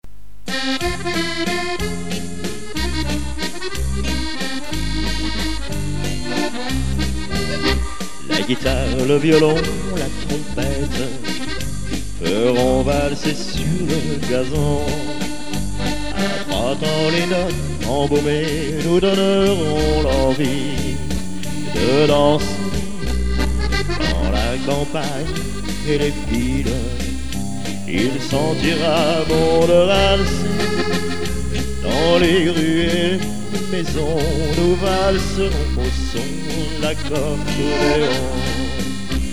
VARIETE FRANCAISE chantée (extraits)
(chantée)